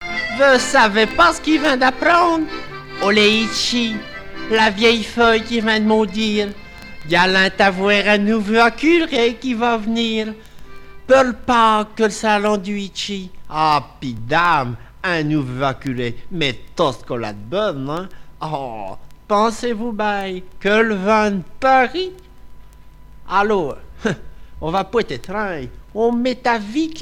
Langue Poitevin-Saintongeais
Catégorie Récit